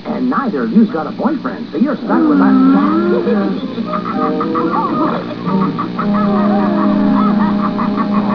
These are all origional sounds that we recorded ourselves, so please be so kind as to not steal them.